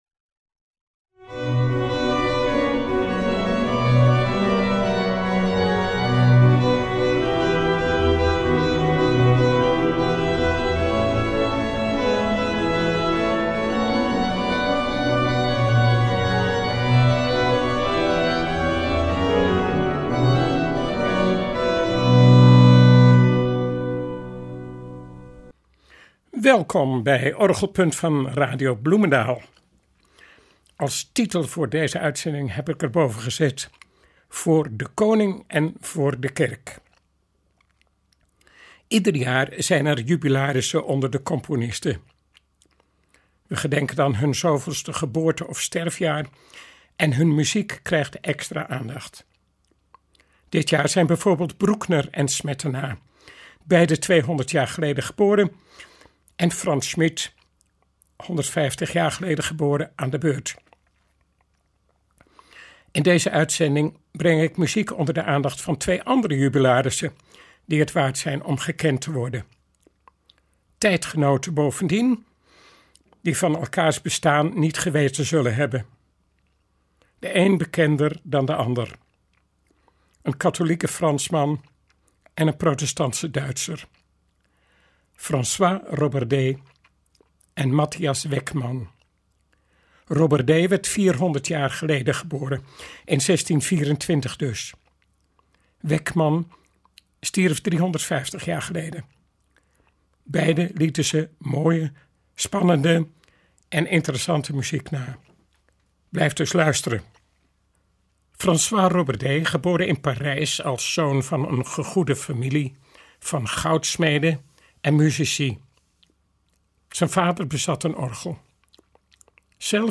Het is een vroeg en hoogstaand voorbeeld van polyfone orgelmuziek.
Later werd hij organist van de Jacobikerk in Hamburg en componeerde veel orgelwerken, cantates en ensemblemuziek. Weckmanns muziek is als het ware een synthese tussen de Italiaanse en de Noord-Duitse stijl.